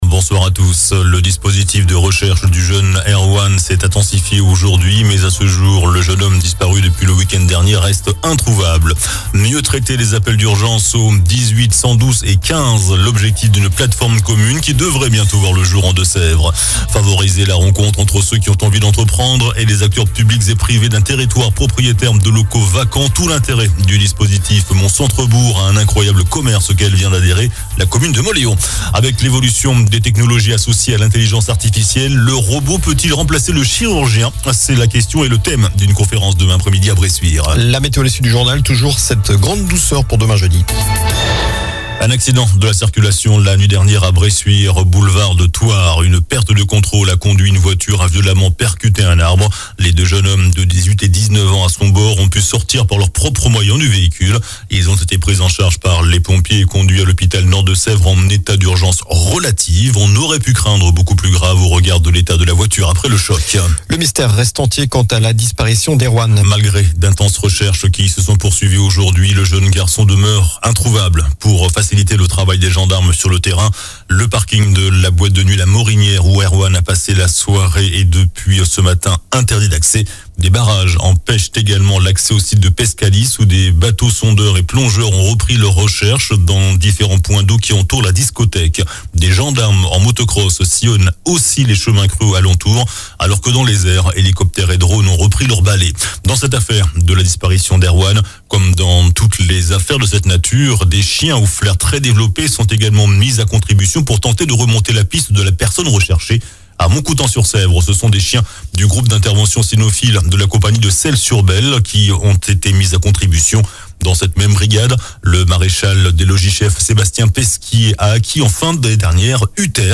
JOURNAL DU MERCREDI 14 FEVRIER ( SOIR )